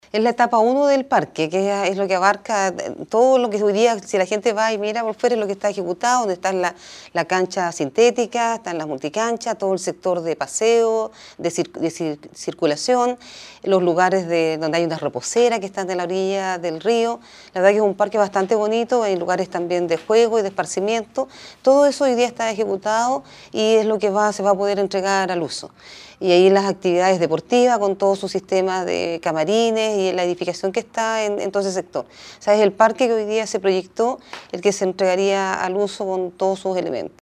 La directora del Serviu, explicó que el Parque debiera estar terminado entre enero y marzo de 2024:  «Todo lo que está ejecutado se podrá entregar al uso”, expresó Isabel de la Vega.